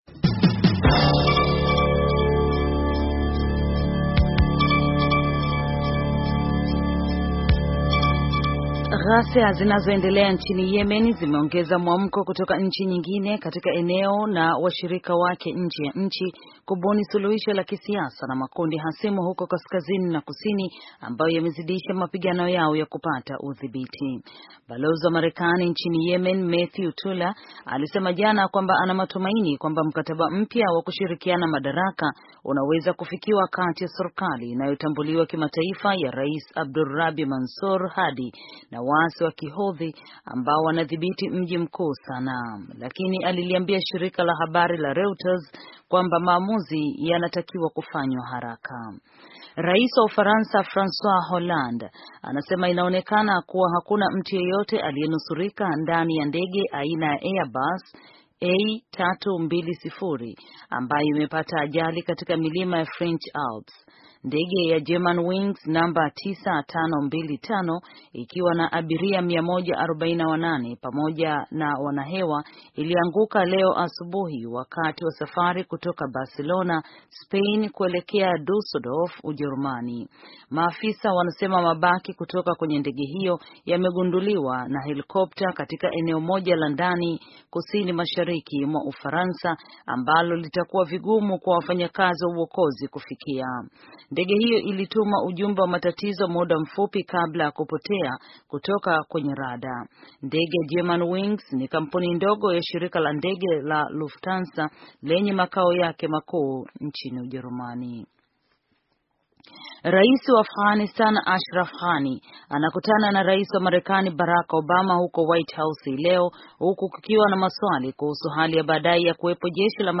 Taarifa ya habari - 5:04